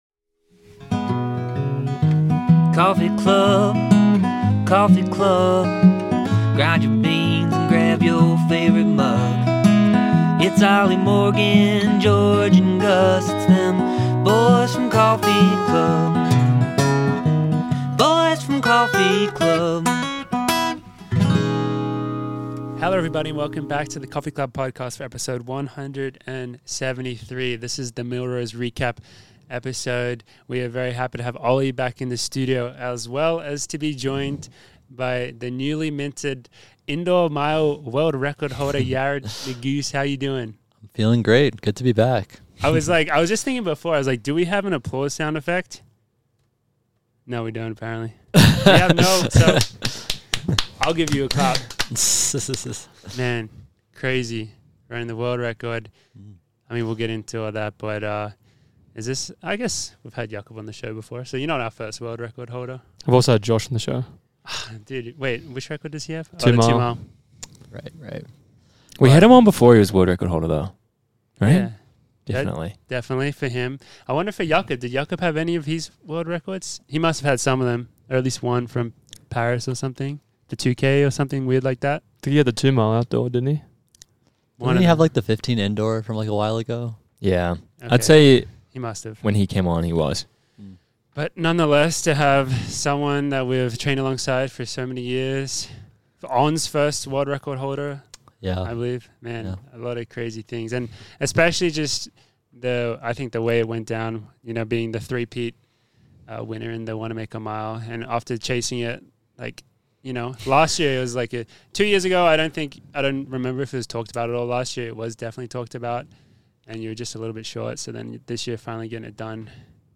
A few runner bois (Morgan McDonald, Oliver Hoare, and George Beamish) sitting down drinking coffee and having a chat.